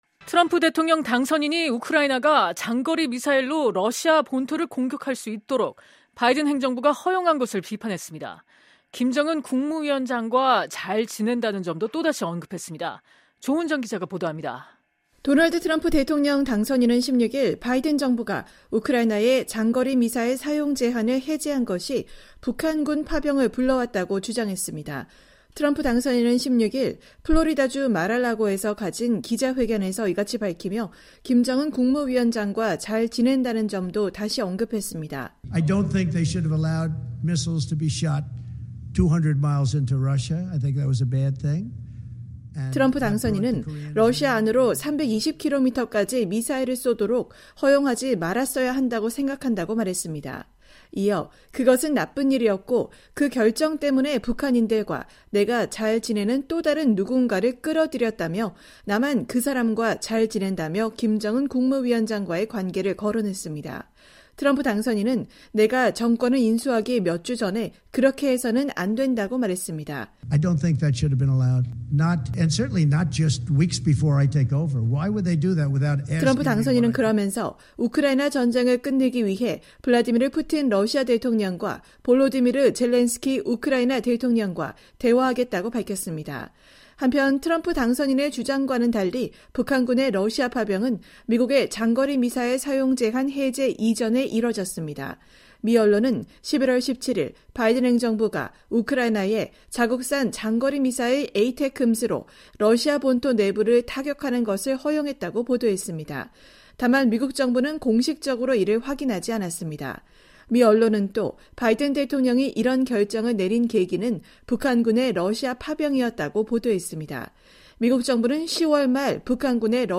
도널드 트럼프 대통령 당선인이 16일 플로리다주 마라라고에서 가진 기자회견에서 발언하고 있다.